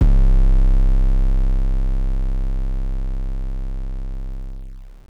TM88 Official808.wav